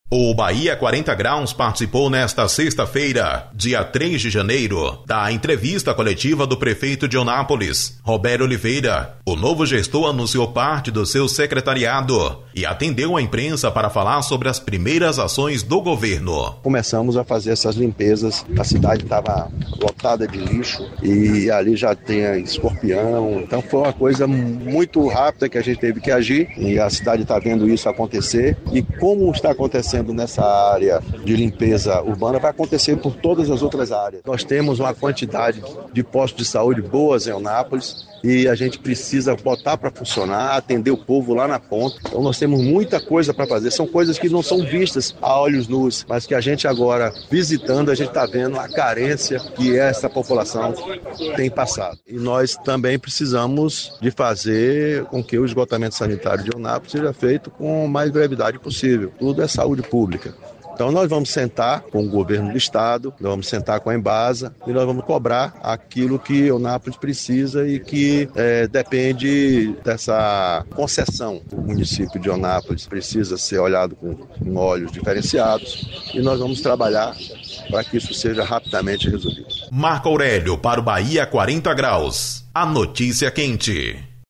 Na manhã de sexta-feira (03), o prefeito de Eunápolis, Robério Oliveira (PSD), anunciou em coletiva de imprensa, oficialmente, nomes que irão compor o seu secretariado no novo mandato.